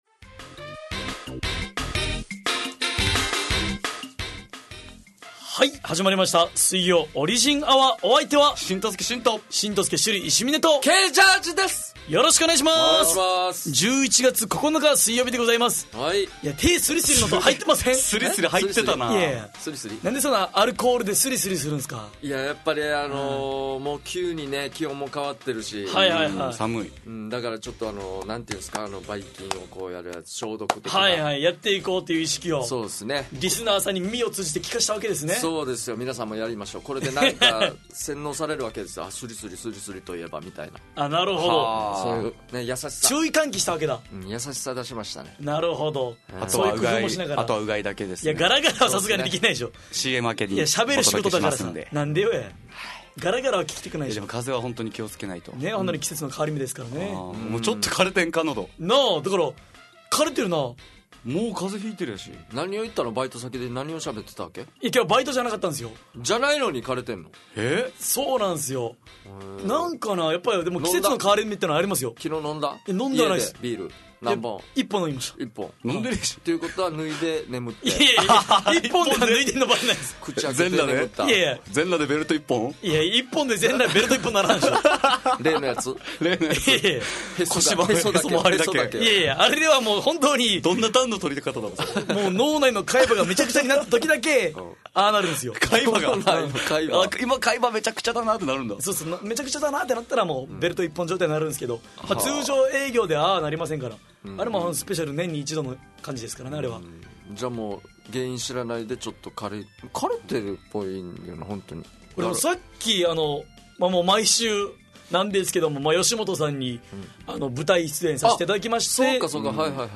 fm那覇がお届けする沖縄のお笑い集団オリジンと劇団O.Z.Eメンバー出演のバラエティ番組!